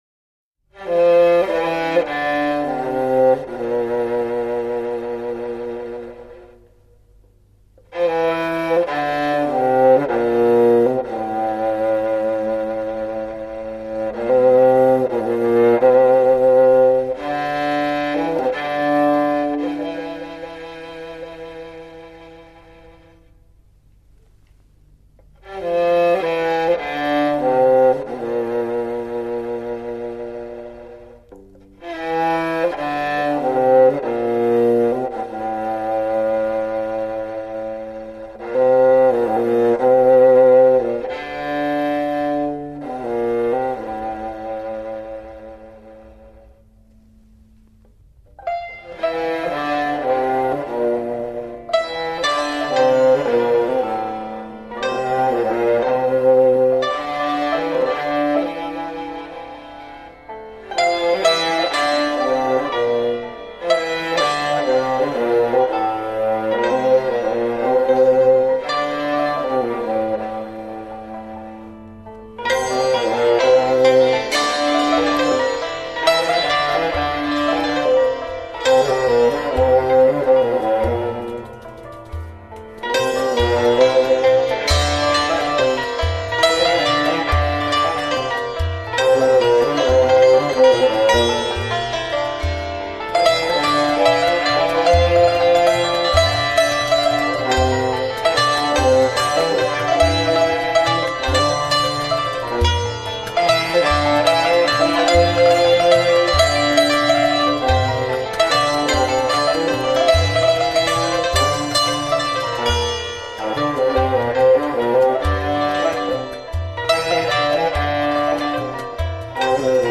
تصنیف